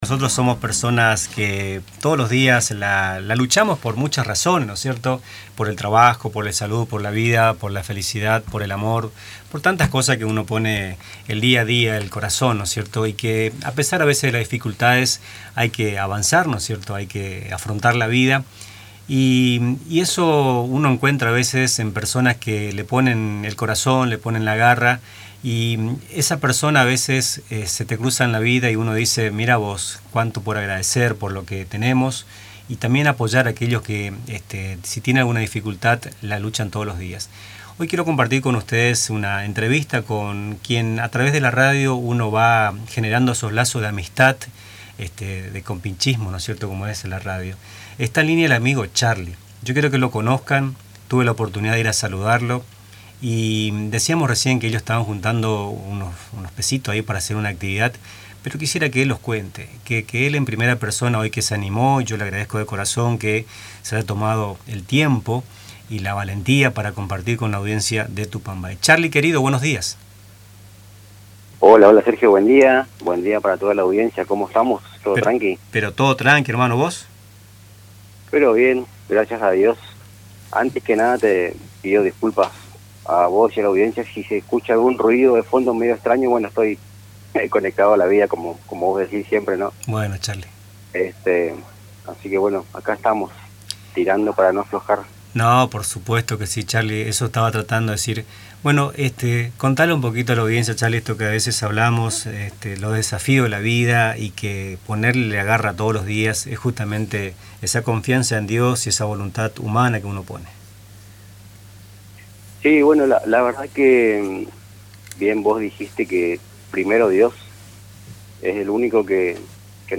En una reciente entrevista con Radio Tupa Mbae